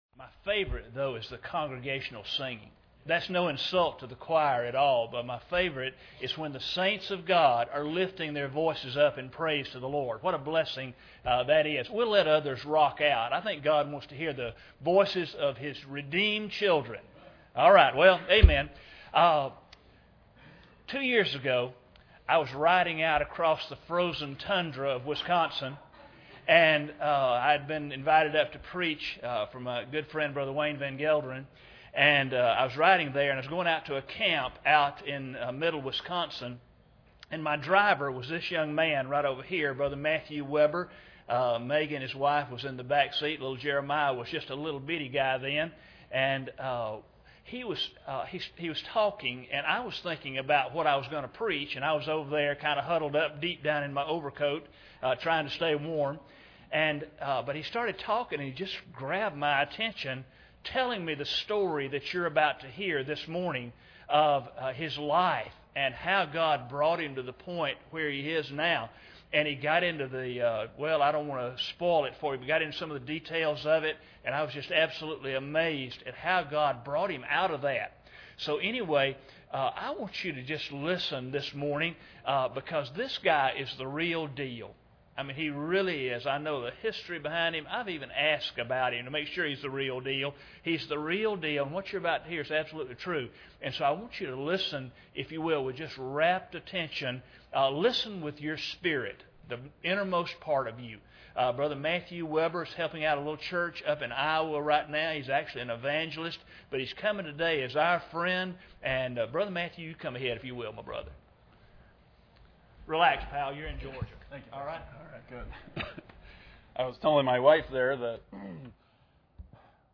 Mark 5:1-7 Service Type: Sunday Morning Bible Text